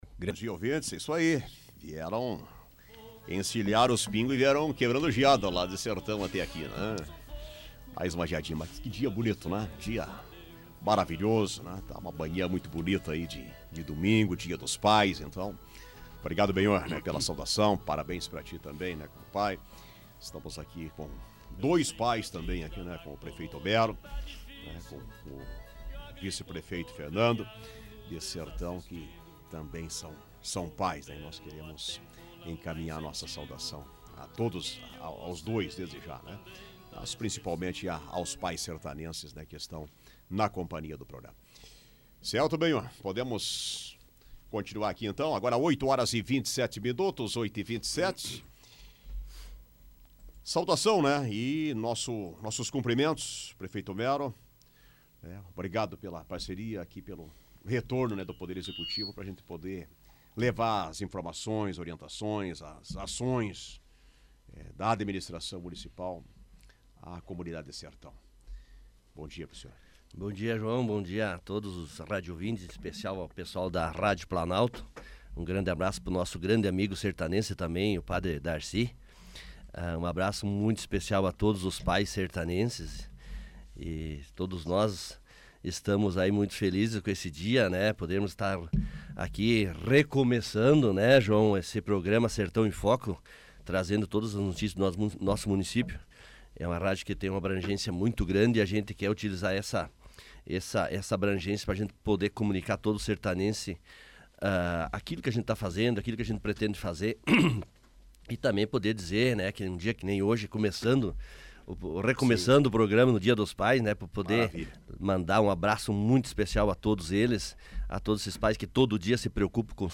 Prefeito e vice de Sertão, Homero Fochesatto e Fernando Haramaq, apresentam ações da administração